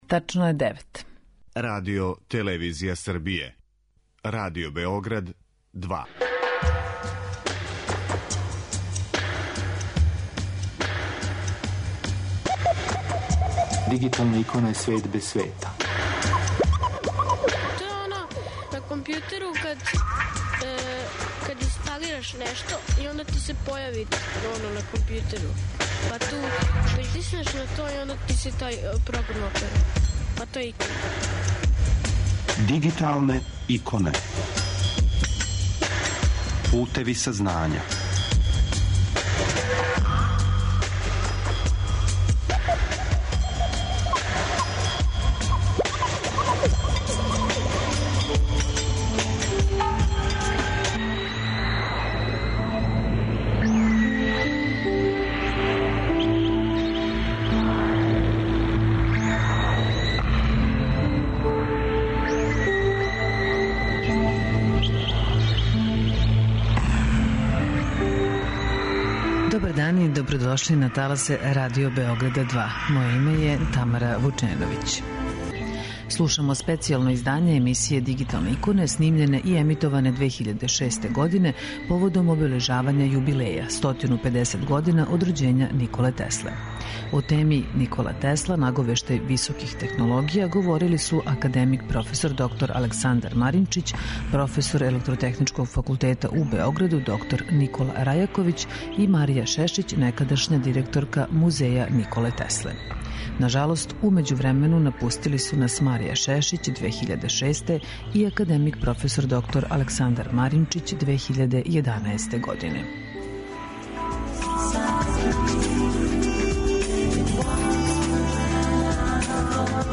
Слушамо специјално издање емисије снимљене и емитоване 2006. године поводом обележавања јубилеја - 150 година од рођења Николе Тесле.